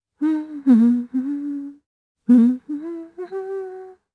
Chrisha-Vox_Hum_jp.wav